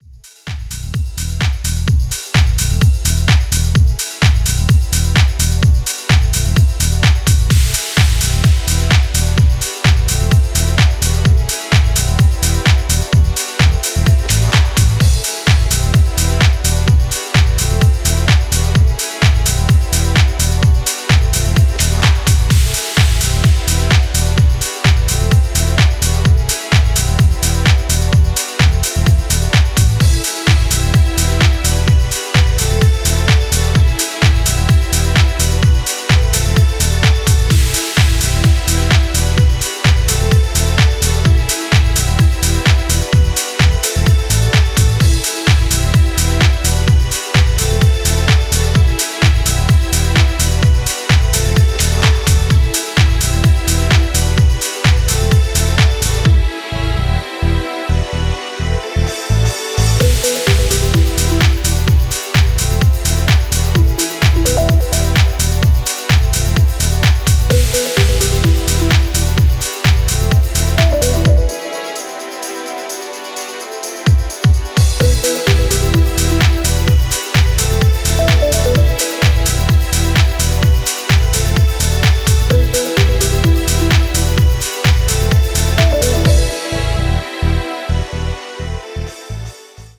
UKレイヴとUSハウスの折衷を試みるような、ピークタイム仕様の意欲作です。